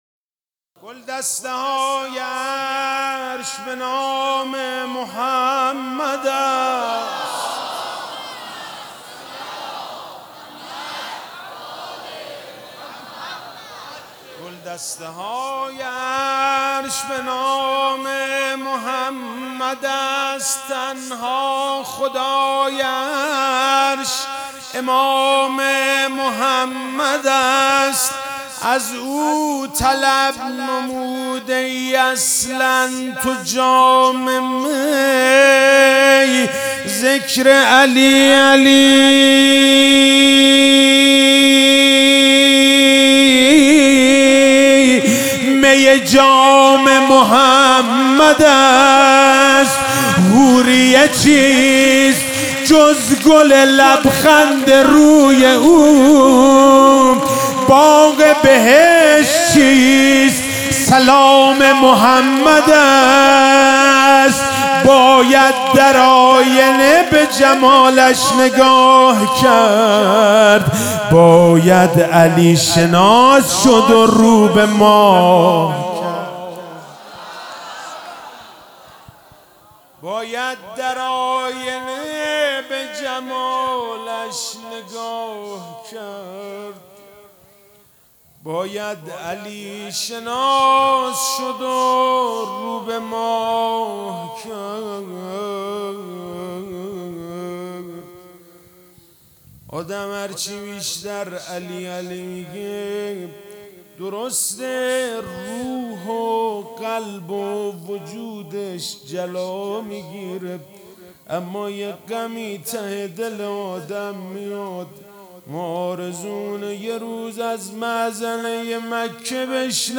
میلاد پیامبراکرم(ص)وامام صادق(ع) - مدح - گلدسته های عرش به نام محمد است
میلاد پیامبراکرم(ص)وامام صادق(ع)1400